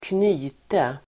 Uttal: [²kn'y:te]